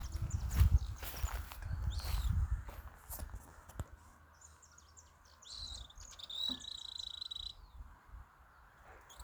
Lark-like Brushrunner (Coryphistera alaudina)
Location or protected area: San Pedro (departamento Federación)
Condition: Wild
Certainty: Observed, Recorded vocal